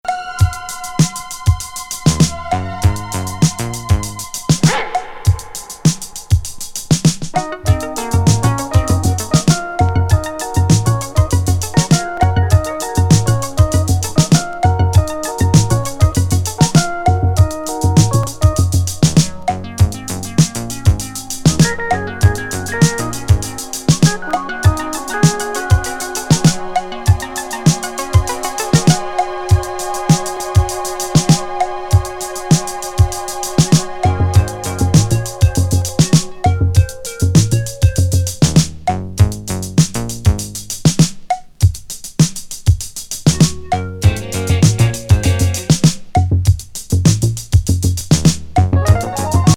ワイルド・ロッキン